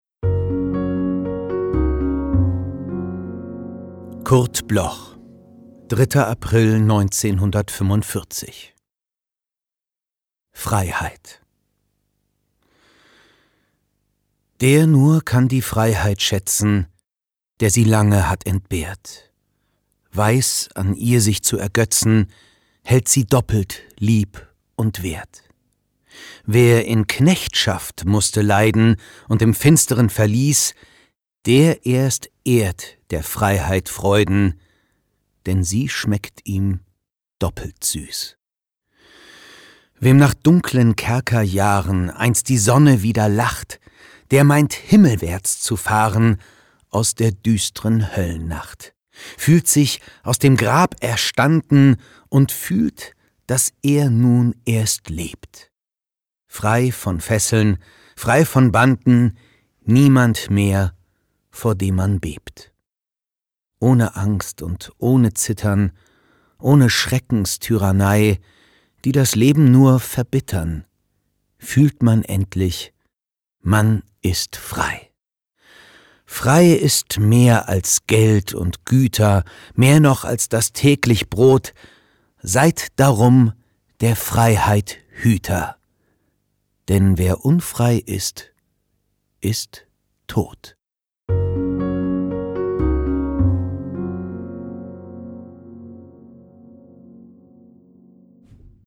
vorgetragen